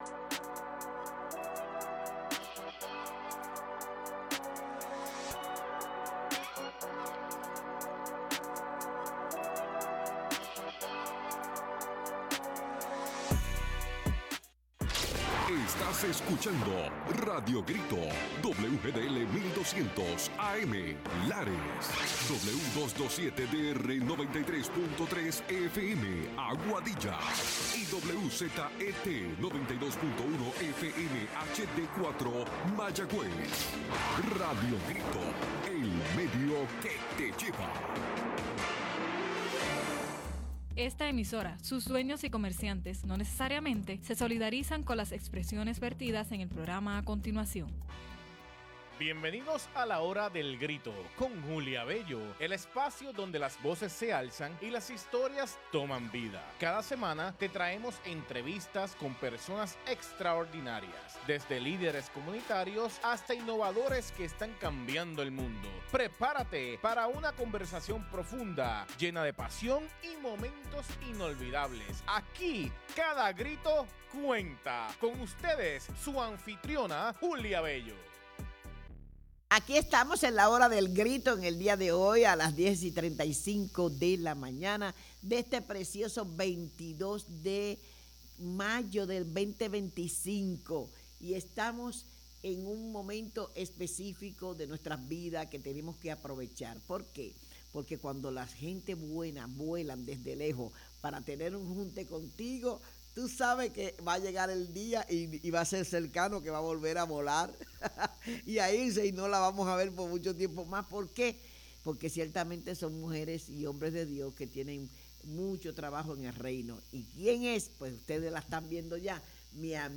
¡Una conversación que encendió el alma!